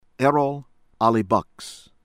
EH -rohl ah-lee- BUHKS